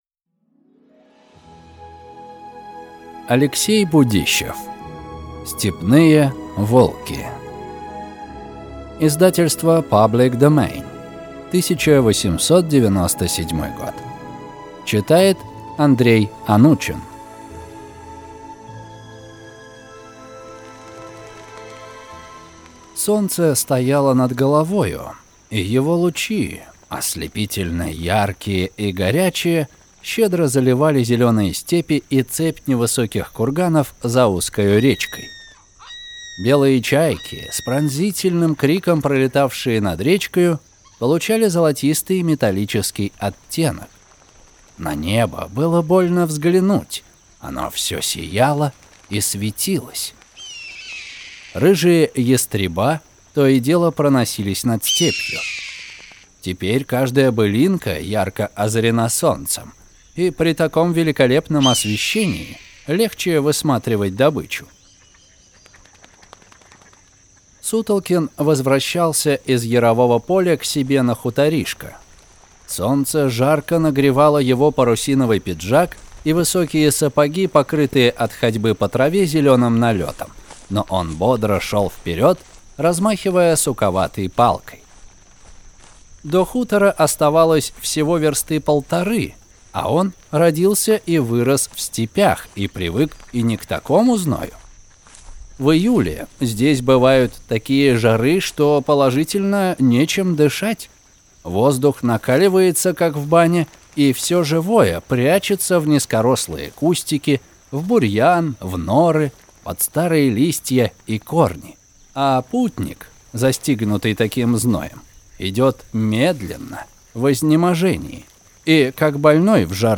Аудиокнига Степные волки | Библиотека аудиокниг